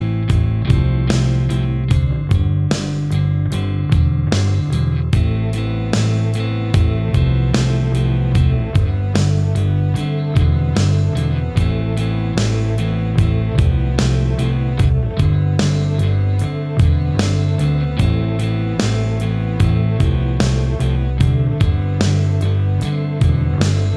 No Guitars Pop (2000s) 5:57 Buy £1.50